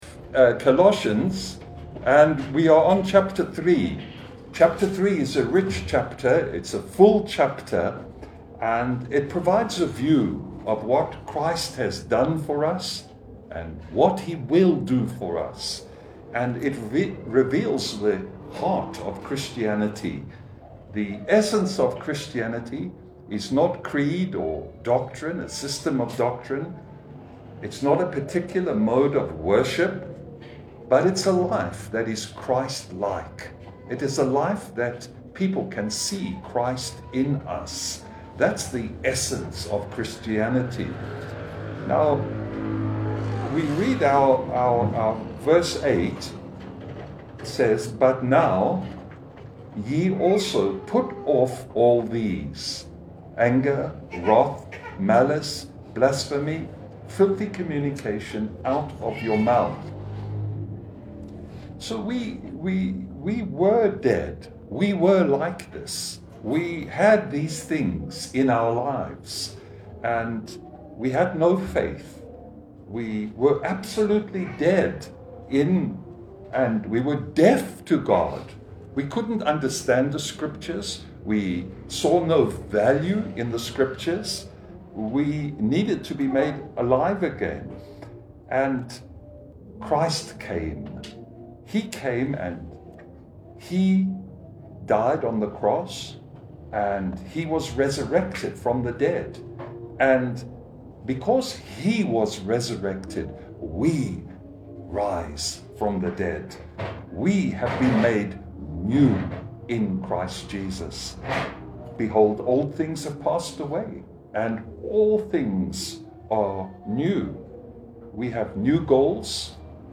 Passage: Col 3: 13 Service Type: Sunday Bible fellowship « Prayer An Open Door Nehemiah